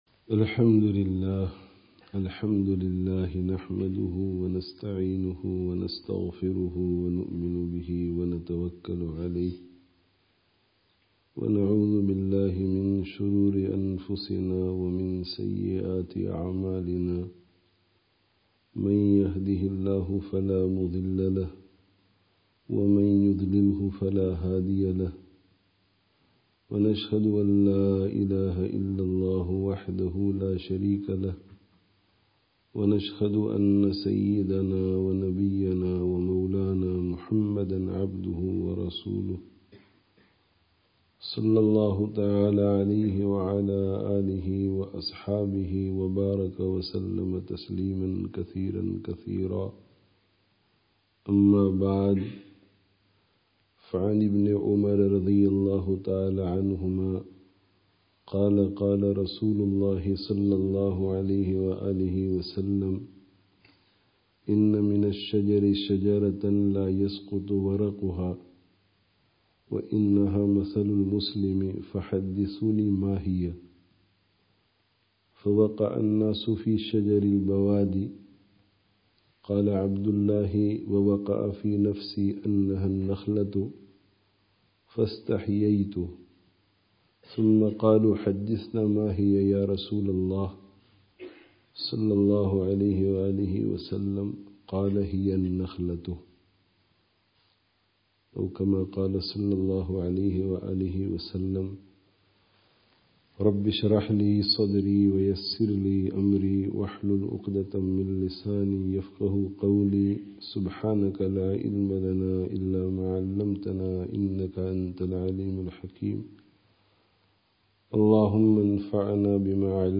Dars of Hadith